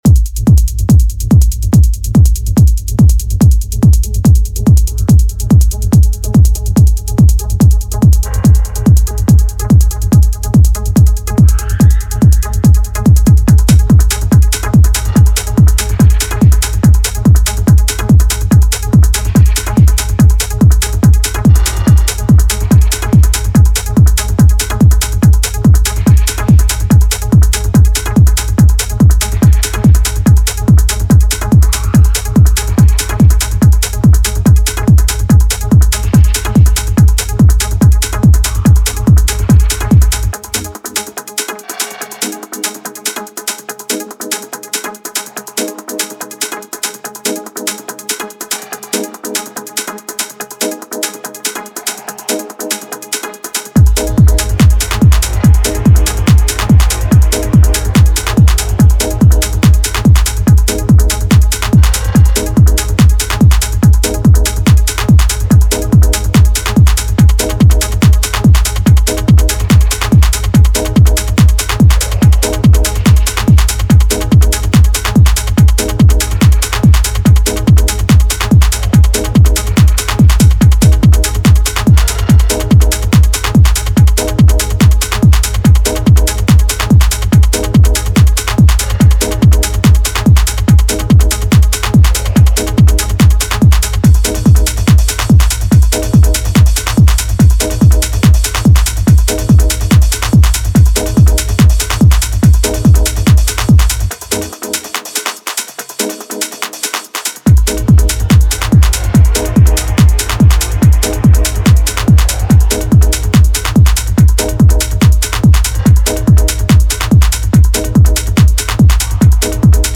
Techno, House und Electronic Music